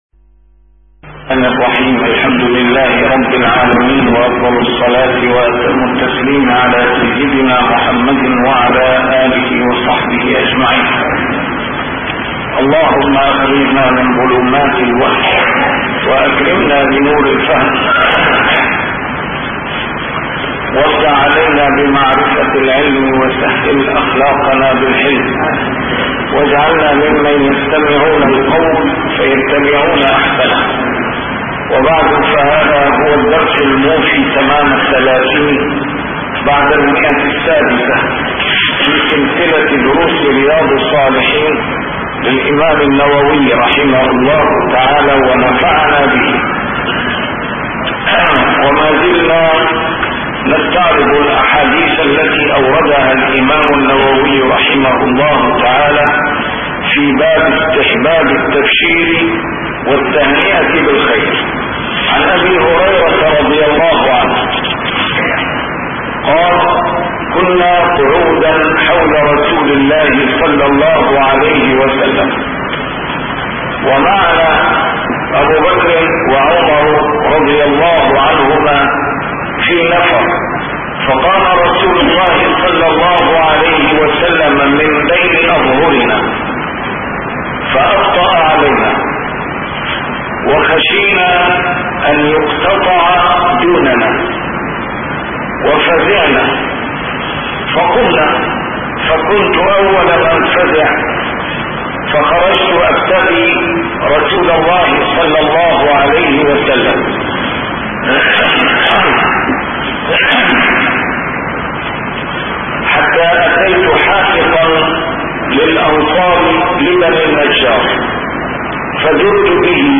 A MARTYR SCHOLAR: IMAM MUHAMMAD SAEED RAMADAN AL-BOUTI - الدروس العلمية - شرح كتاب رياض الصالحين - 630- شرح رياض الصالحين: استحباب التبشير والتهنئة بالخير